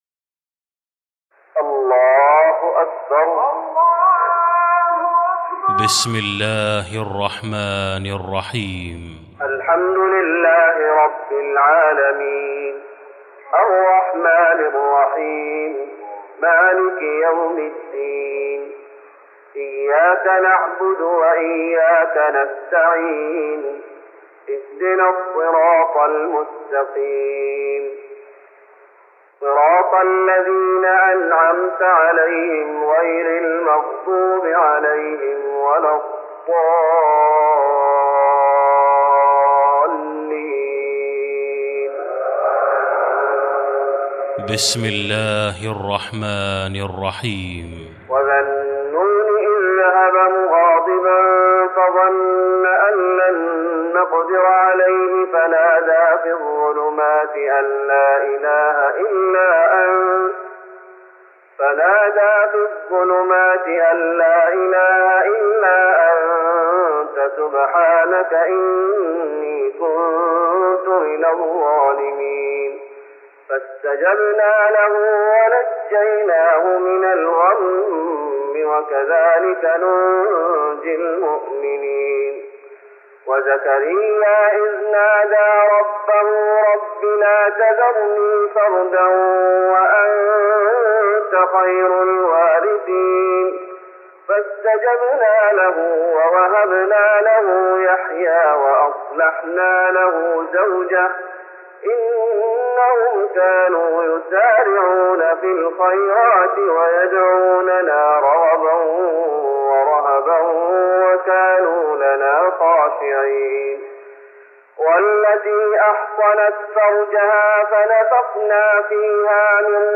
تراويح رمضان 1414هـ من سورتي الأنبياء (87-112) الحج (1-37) Taraweeh Ramadan 1414H from Surah Al-Anbiyaa and Al-Hajj > تراويح الشيخ محمد أيوب بالنبوي 1414 🕌 > التراويح - تلاوات الحرمين